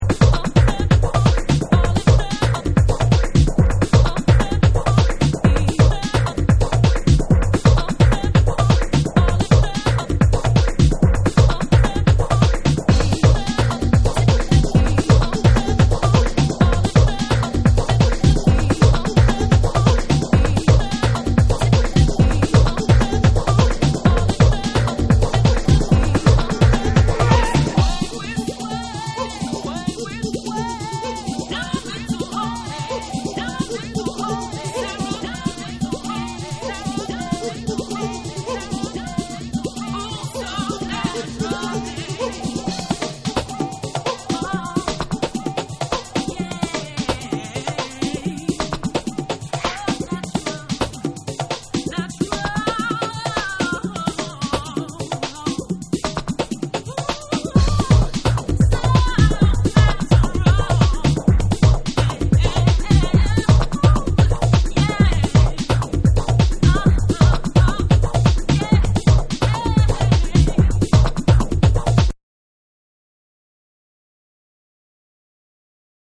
TECHNO & HOUSE / ALL 840YEN